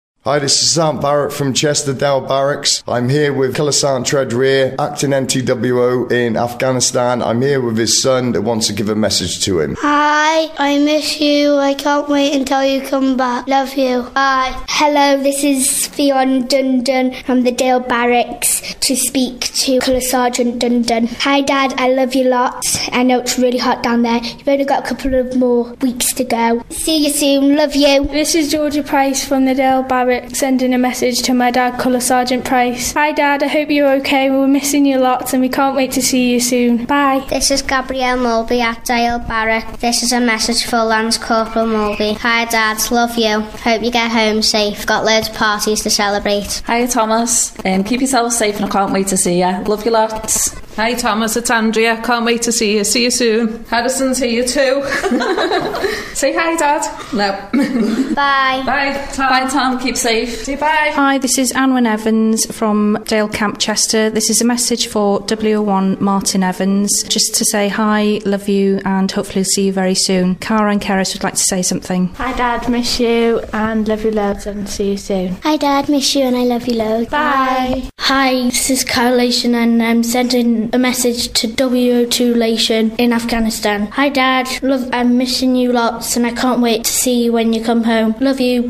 The BFBS ice cream dropped by the Dale Barracks, this is what they recorded.